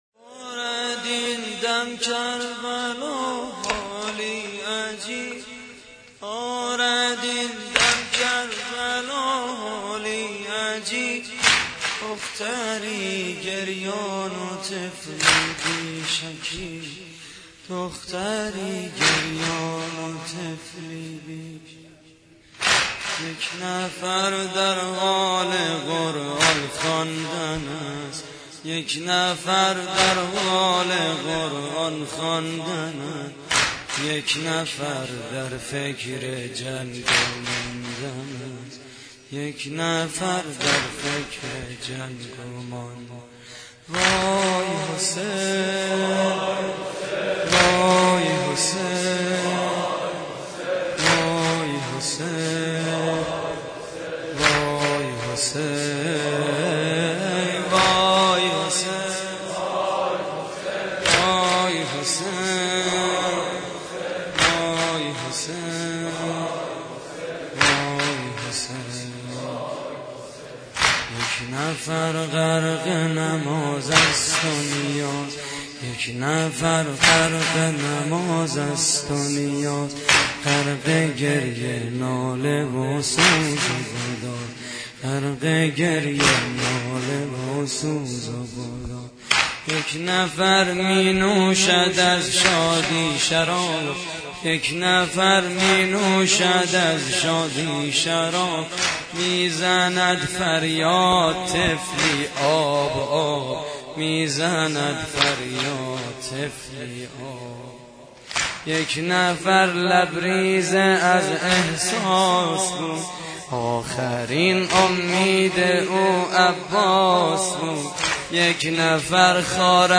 واحد: دارد این دم کربلا حالی عجیب؛ پخش آنلاین |